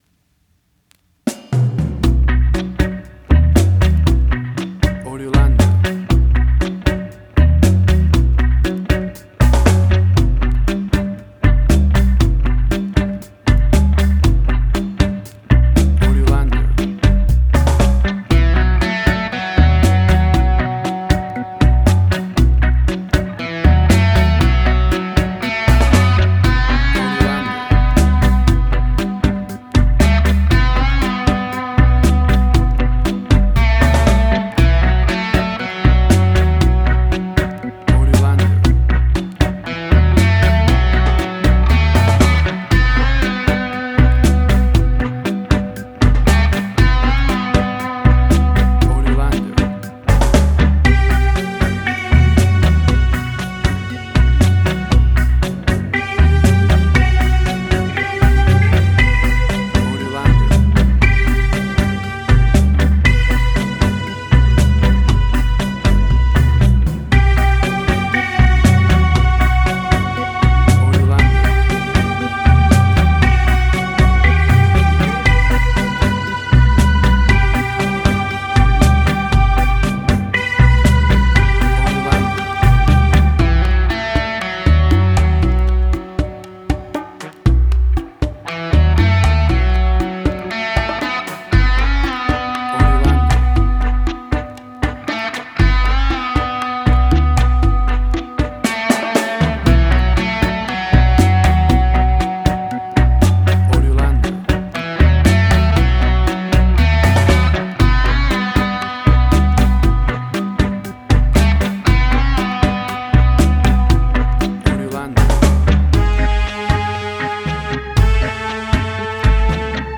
Reggae caribbean Dub Roots
Tempo (BPM): 59